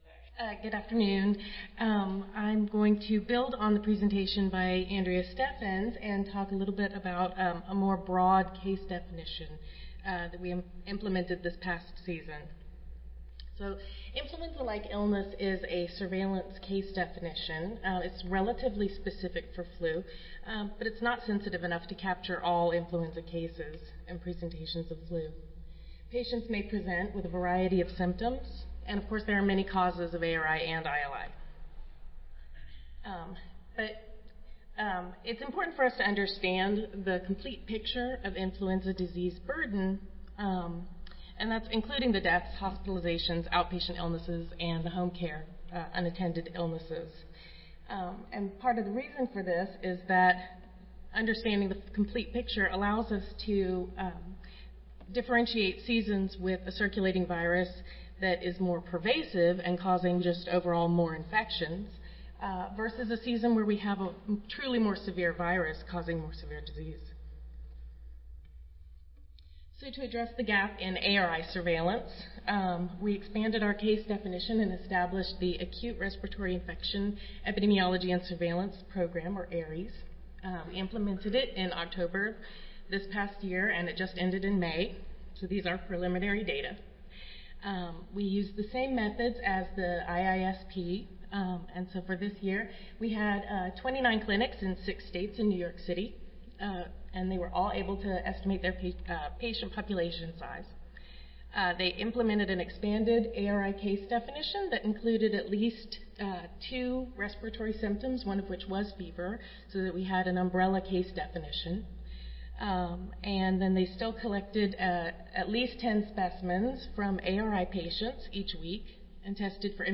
400C, Boise Centre
Audio File Recorded Presentation